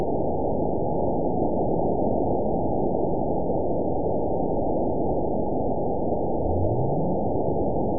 event 912329 date 03/24/22 time 19:46:37 GMT (3 years, 7 months ago) score 9.65 location TSS-AB01 detected by nrw target species NRW annotations +NRW Spectrogram: Frequency (kHz) vs. Time (s) audio not available .wav